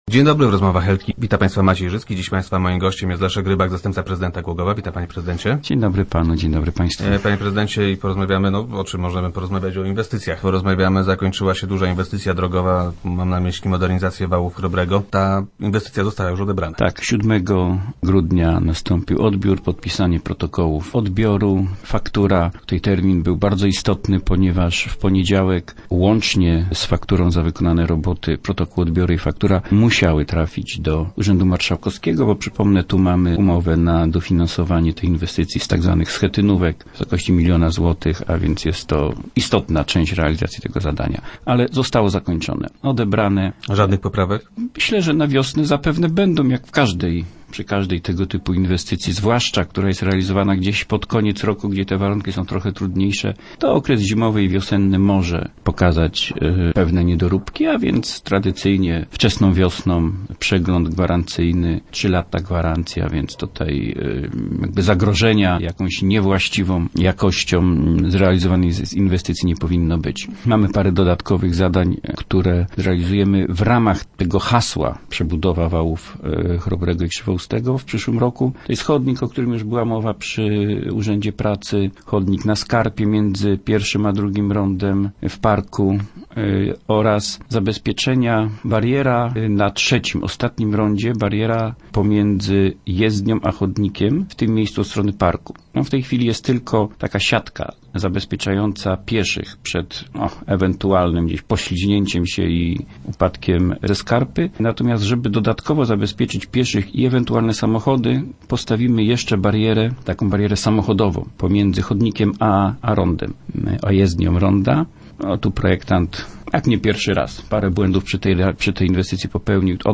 Większych uwag nie było – informuje Leszek Rybak, zastępca prezydenta, który był gościem Rozmów Elki.